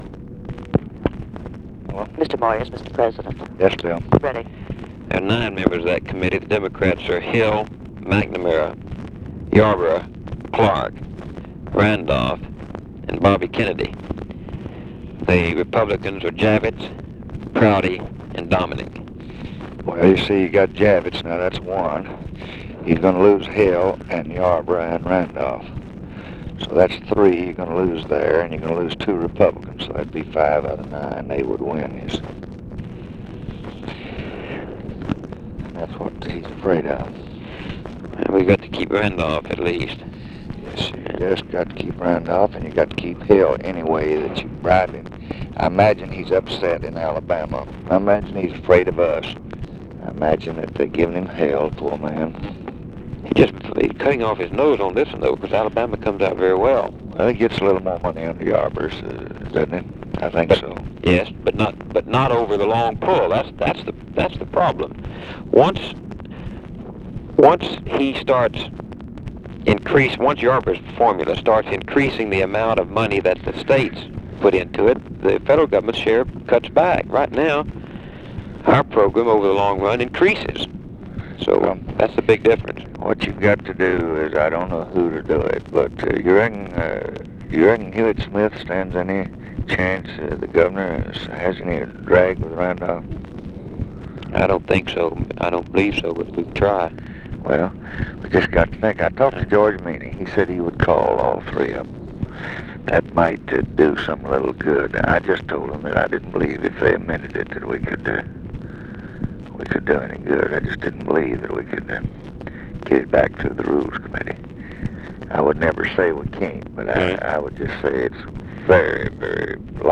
Conversation with BILL MOYERS, April 1, 1965
Secret White House Tapes